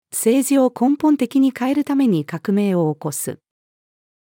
政治を根本的に変えるために革命を起こす。-female.mp3